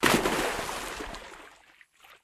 water_bigsplash.wav